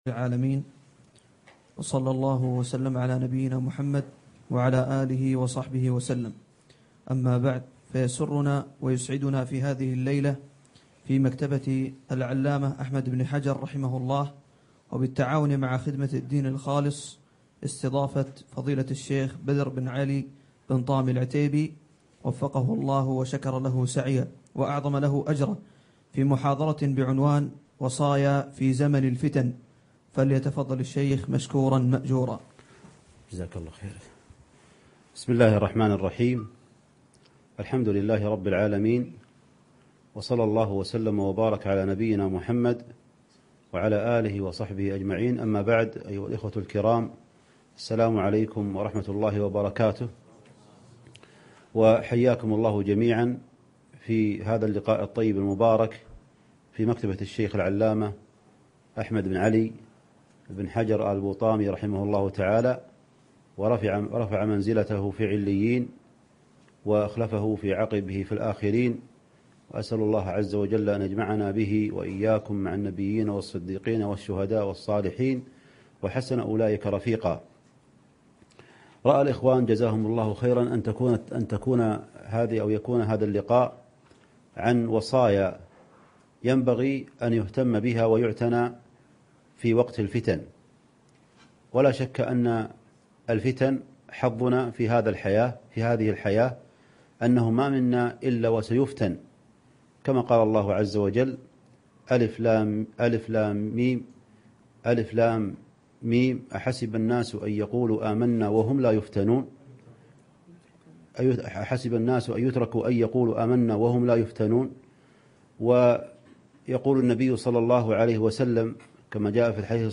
أقيمت المحاضرة في دولة قطر